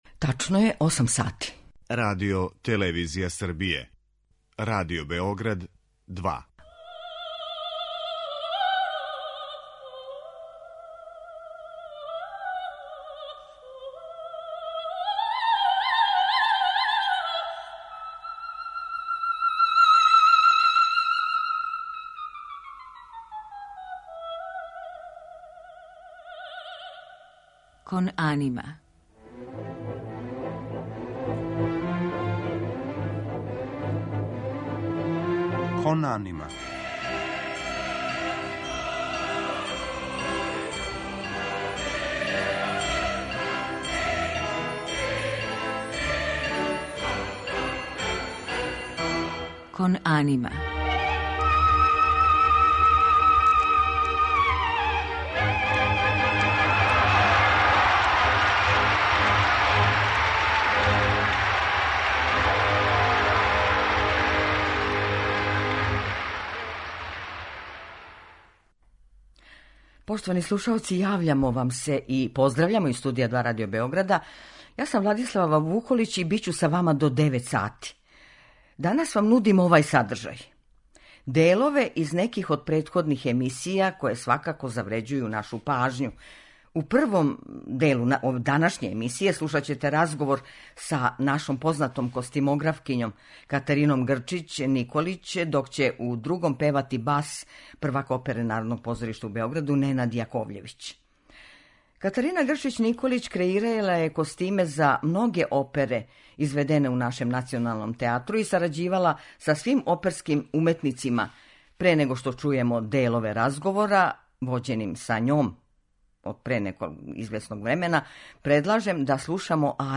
Занимљиви разговори о опери и оперски фрагменти